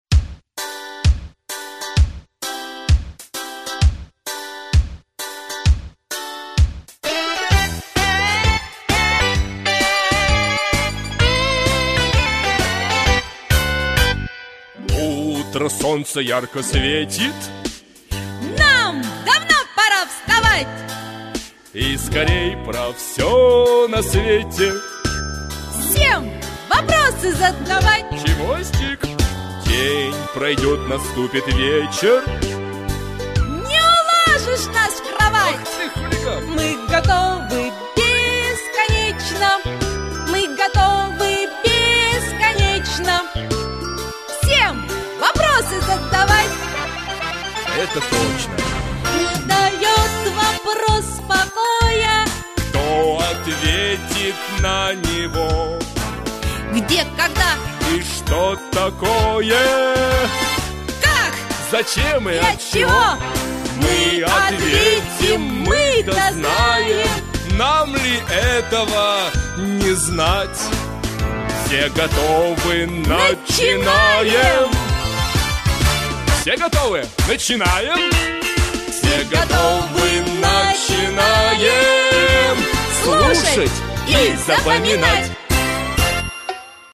Аудиокнига Обитатели океанов | Библиотека аудиокниг